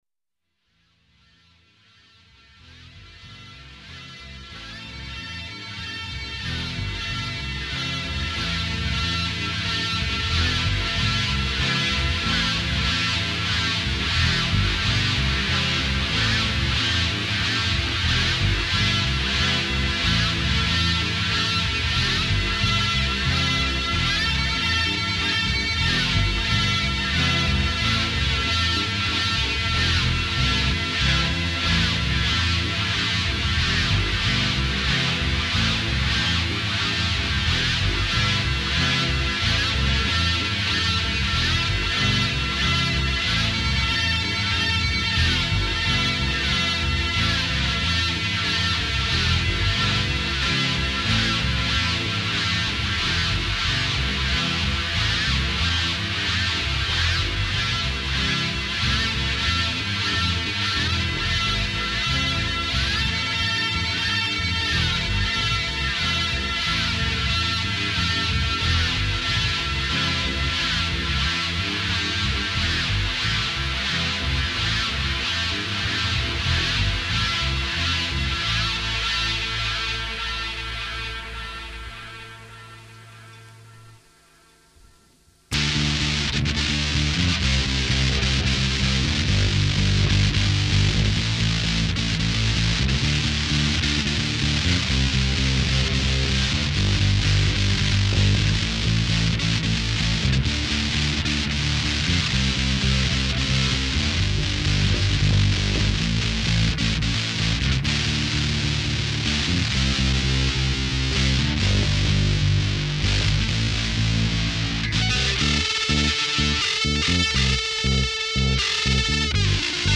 Guitar, Vocals, Percussion
Bass, Vocals, Percussion